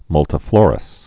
(mŭltə-flôrəs)